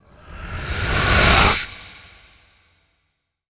dark_wind_growls_05.wav